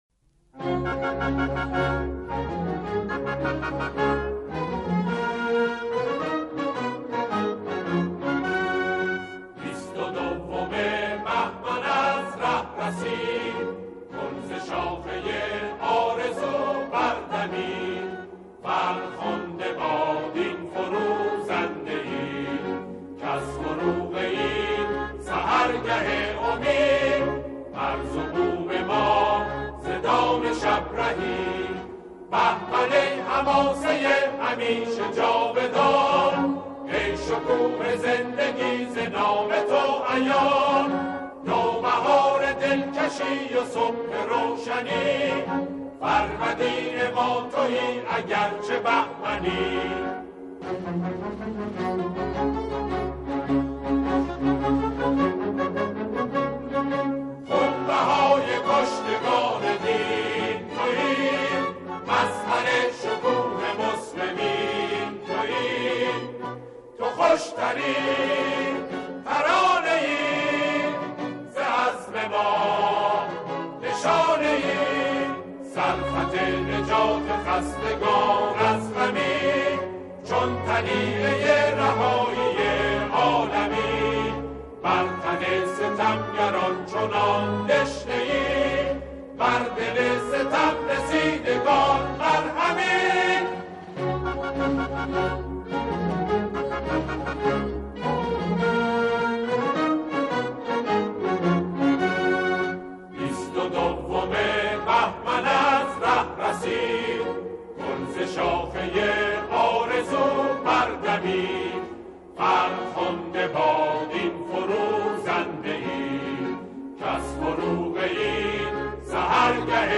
سرودهای دهه فجر
همخوانی